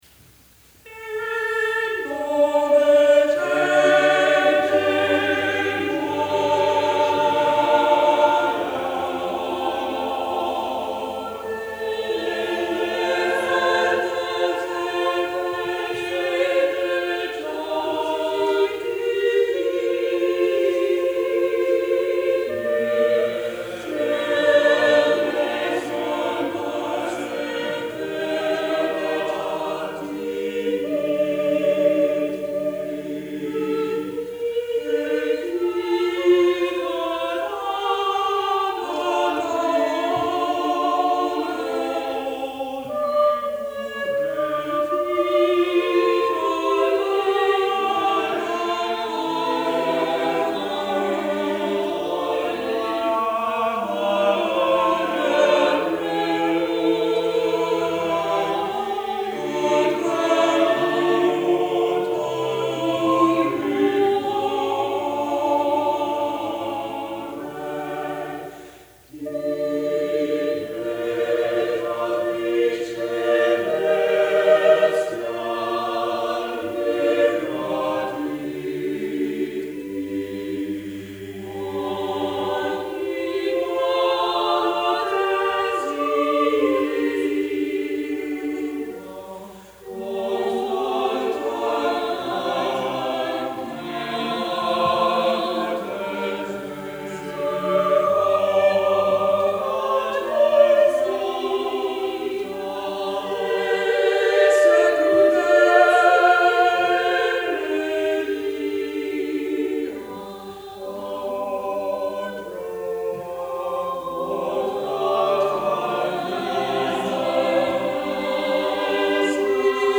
. 21 in all and all for 5 voices.
These late madrigals of Baccusi exemplify the great flexibility of contrapuntal line found in the late polyphonic madrigal.
| Vocal Ensemble